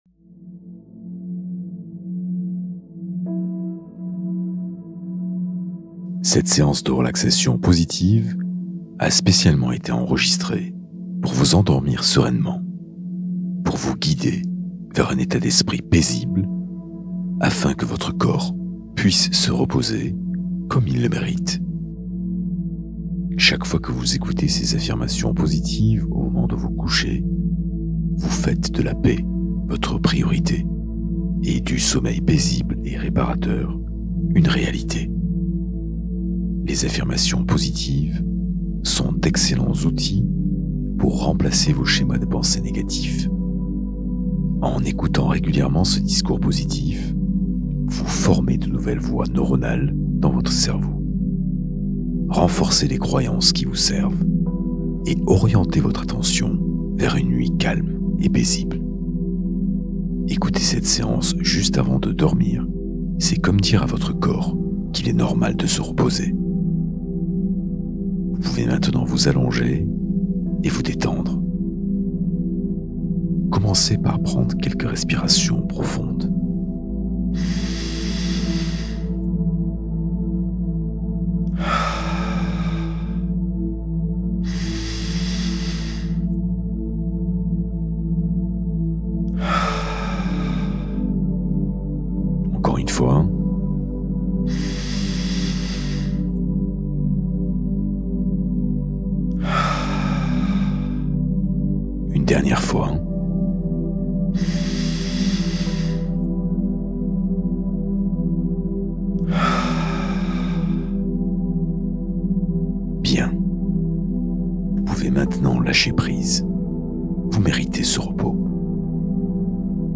777 Hz : fréquence de soutien pour l’équilibre et la clarté